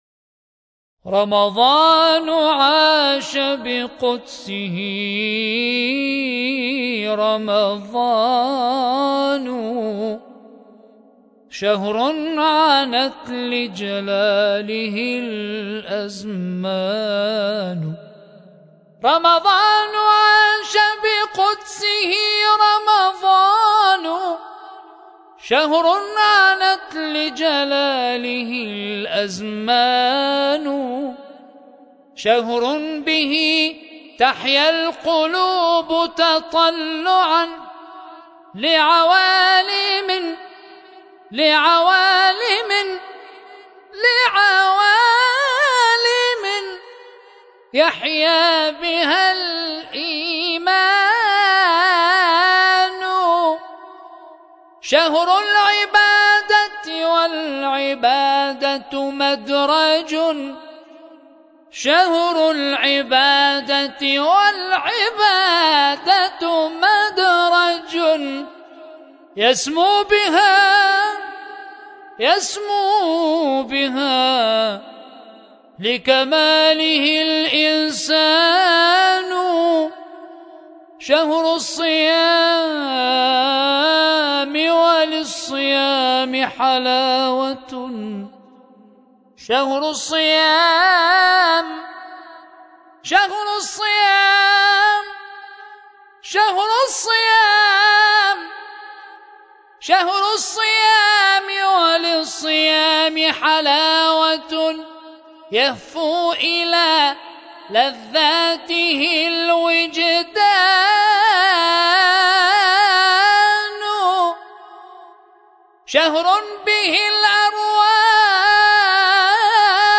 رمضان عاش بقدسه ـ ابتهالات